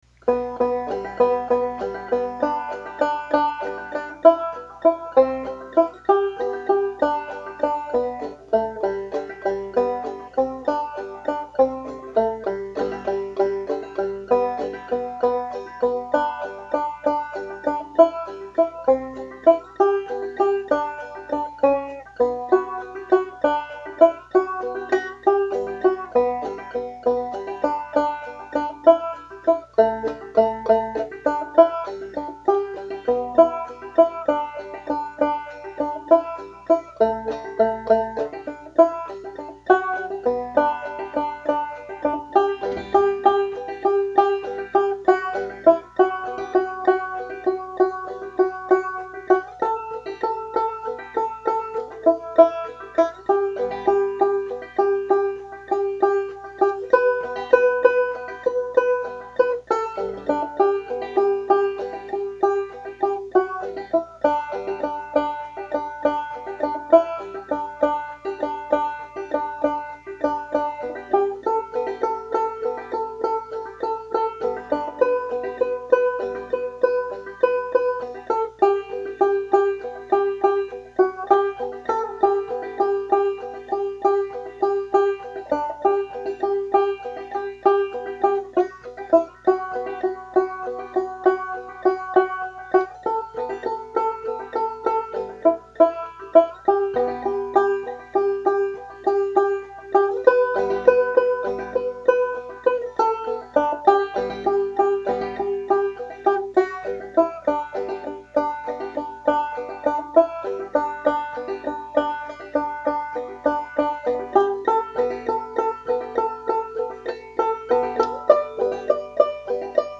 This was very difficult to play on the banjo, in part because you need to play pretty far up the neck, which I haven't done much of yet. It took many takes to get something that sounded good.